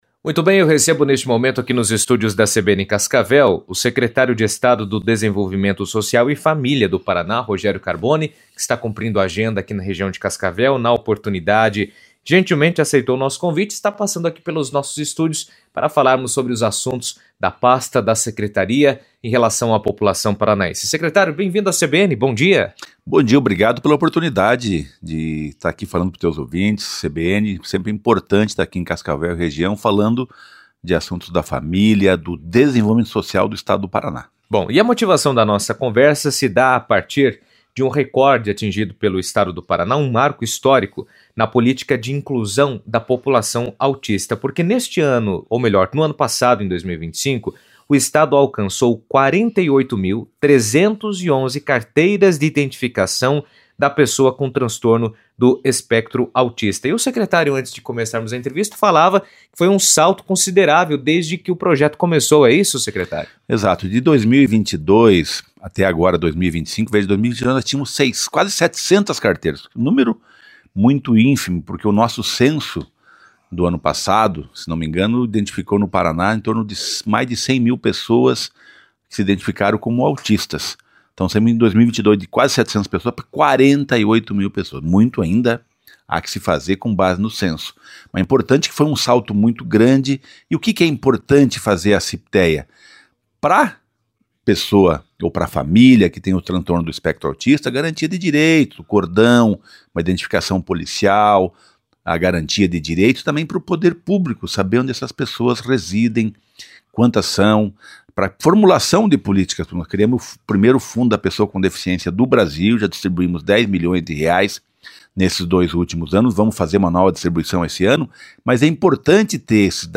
O Paraná atingiu a marca histórica de 48.311 carteiras do autista emitidas nos últimos cinco anos, garantindo mais direitos e acessibilidade às pessoas com transtorno do espectro autista. Rogério Carboni, secretário de Estado do Desenvolvimento Social e Família, comentou sobre o avanço e a importância do documento durante entrevista à CBN.